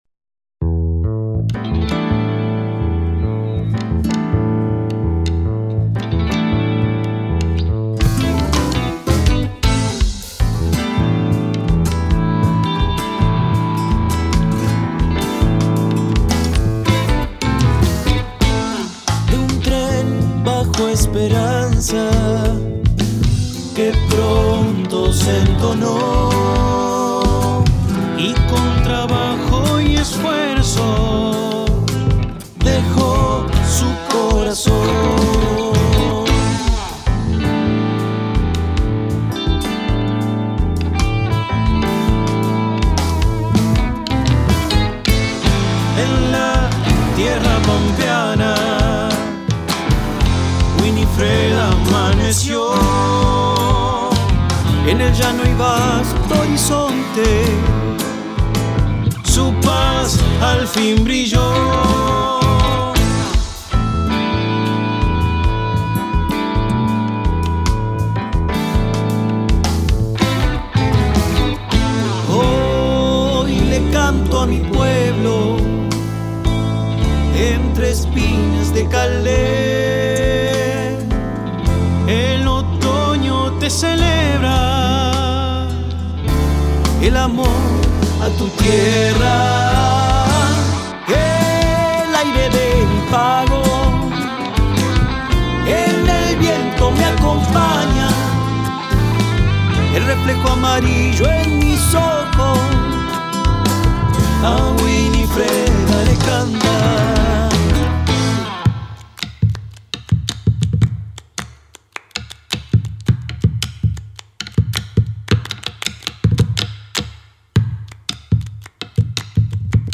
Durante los festejos centrales fue cantada por “Somos Trío”
Los cantantes le dieron al tema un aire de chacarera.